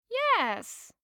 알림음(효과음) + 벨소리
알림음 8_WomanYes5.mp3